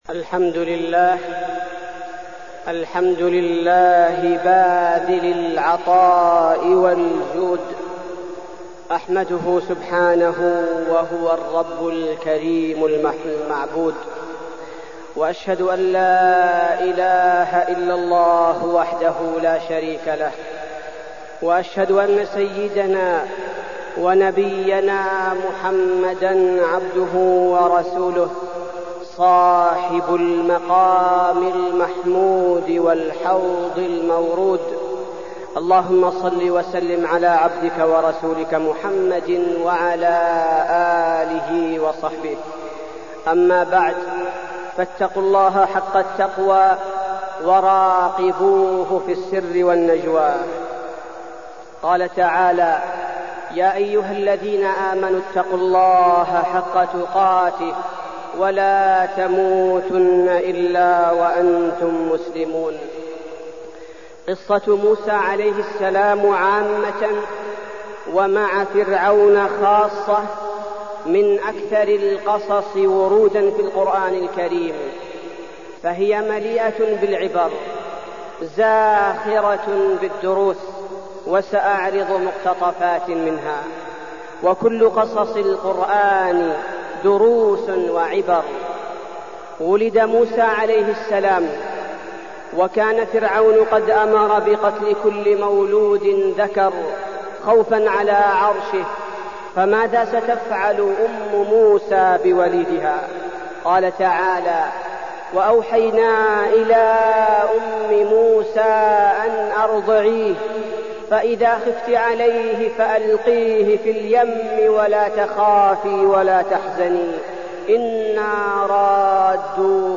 تاريخ النشر ٧ محرم ١٤٢٠ هـ المكان: المسجد النبوي الشيخ: فضيلة الشيخ عبدالباري الثبيتي فضيلة الشيخ عبدالباري الثبيتي قصة موسى عليه السلام